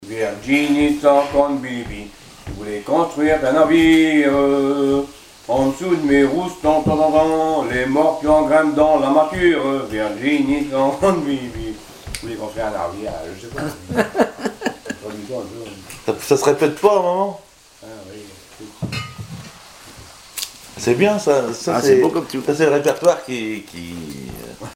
Genre strophique
Témoignage et chansons maritimes
Pièce musicale inédite